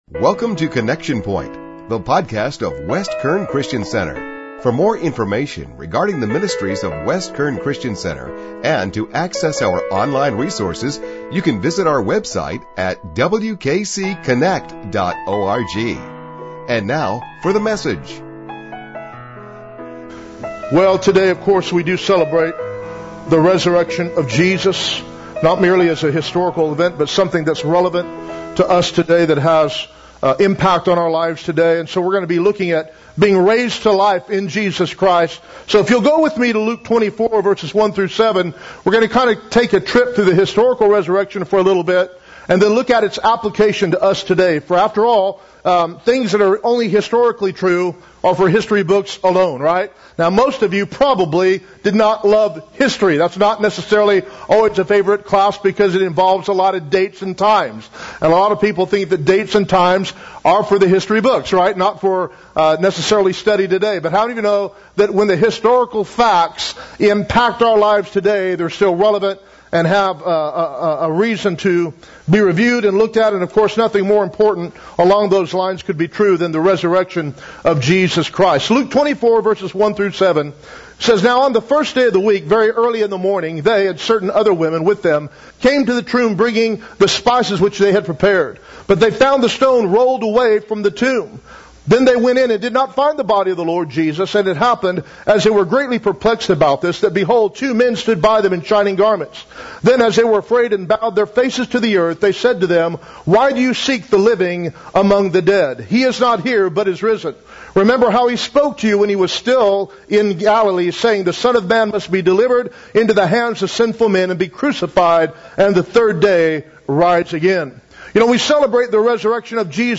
Easter Service 2017
easter-service-raised-to-life.mp3